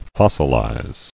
[fos·sil·ize]